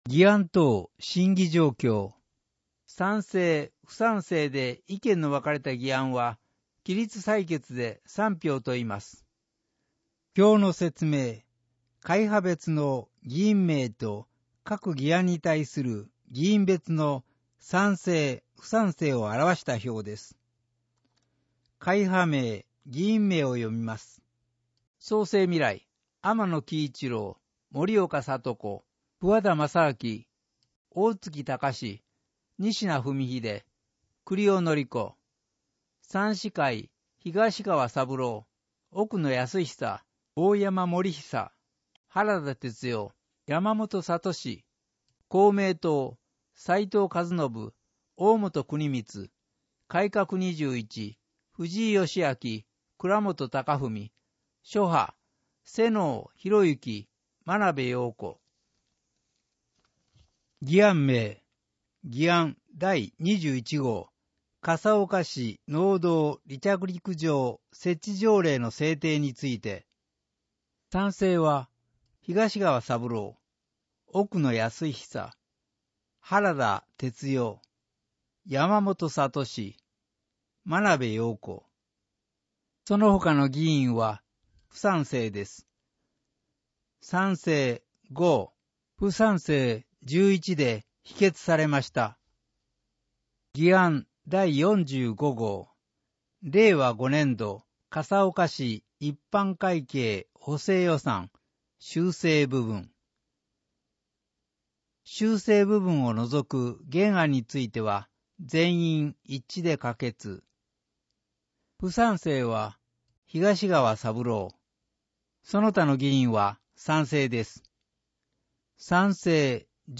市議会だより177号（音訳版）